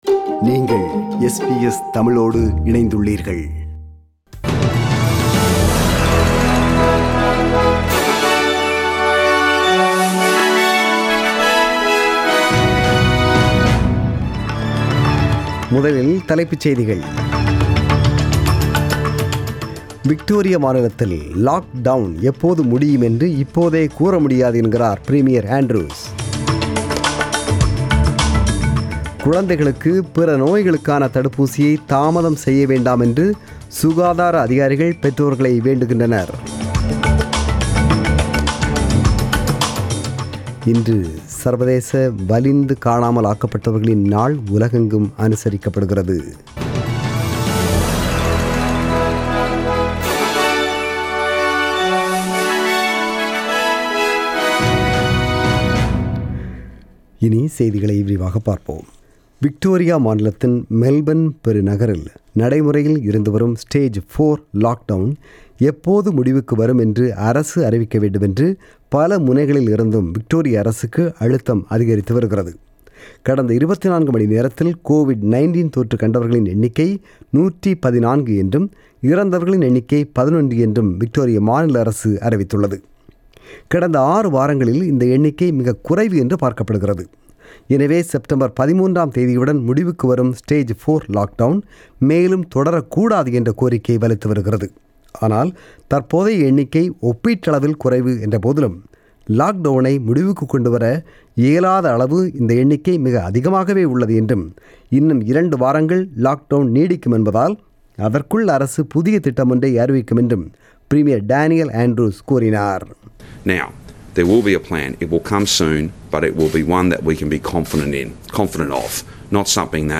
The news bulletin was broadcasted on 30 August 2020 (Sunday) at 8pm.